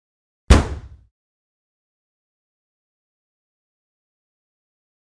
Car Door Sound
transport
Car Door